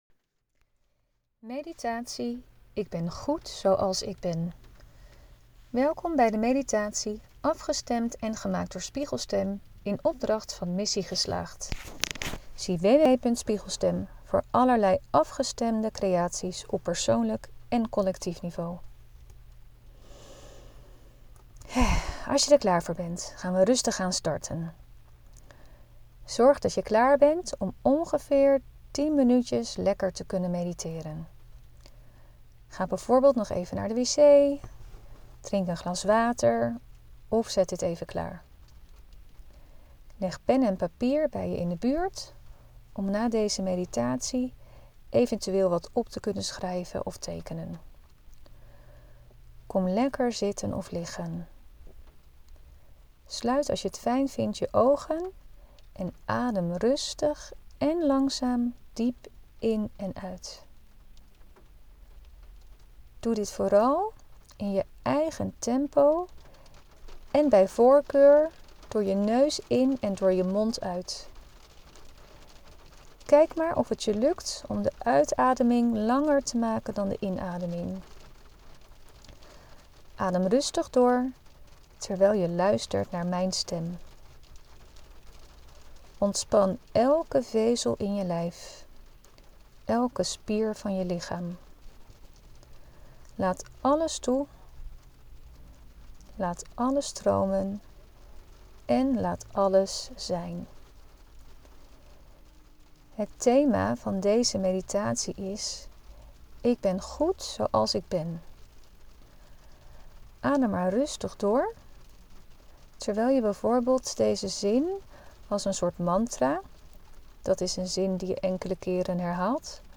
Meditatie Wat als jij denkt in mogelijkheden vanuit positieve overtuigingen